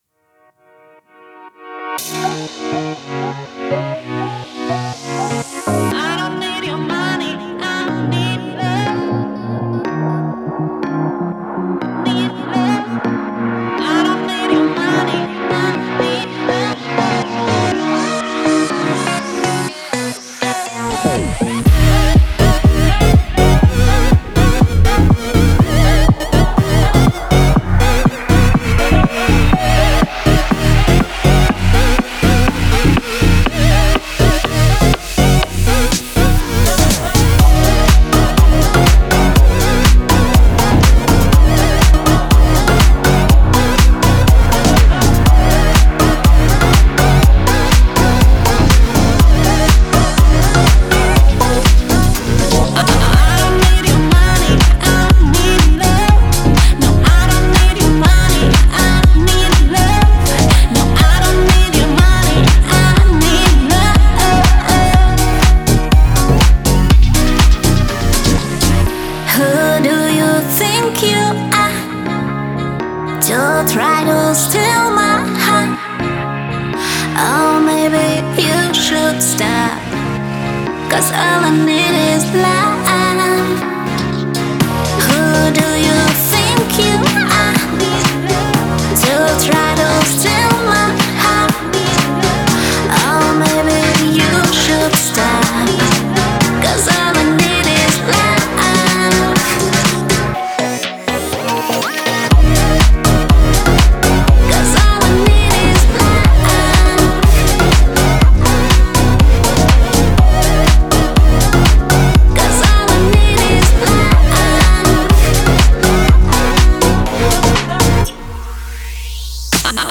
это зажигательная трек в жанре EDM